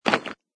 stone.mp3